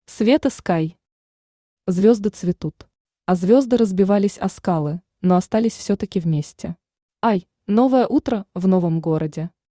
Аудиокнига Звёзды цветут | Библиотека аудиокниг
Aудиокнига Звёзды цветут Автор Света Скай Читает аудиокнигу Авточтец ЛитРес.